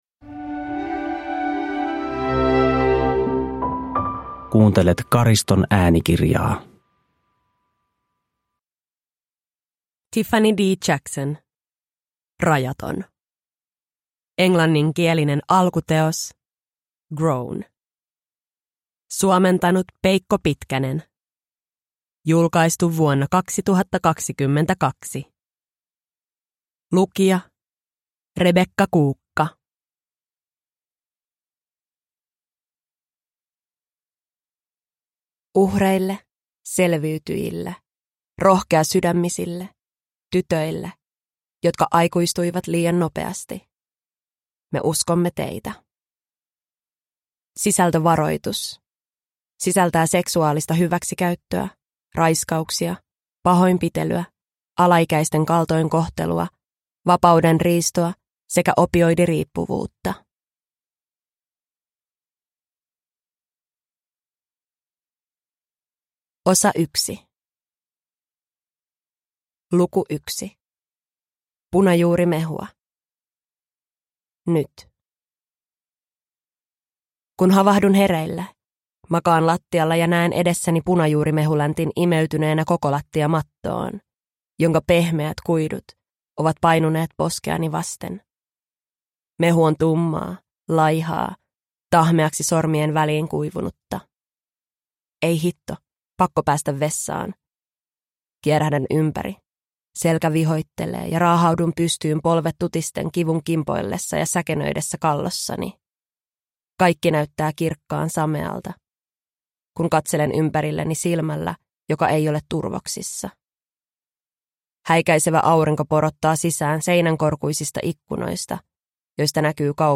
Rajaton – Ljudbok – Laddas ner